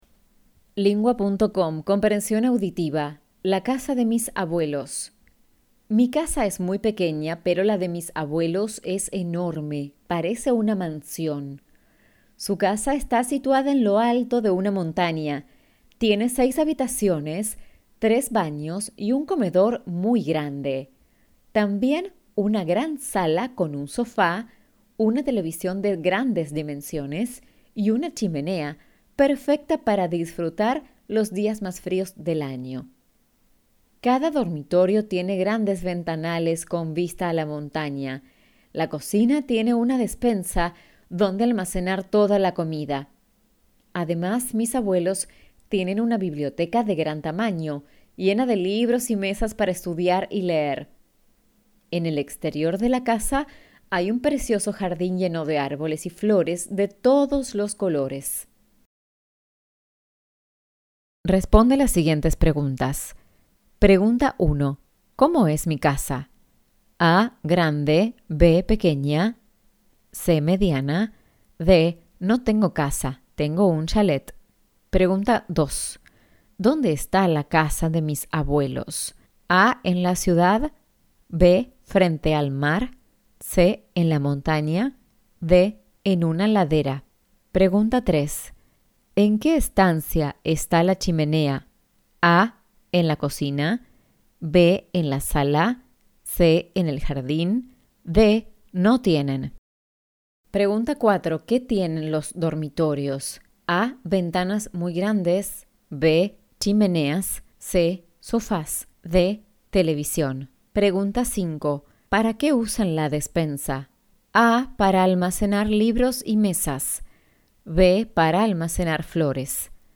Argentinien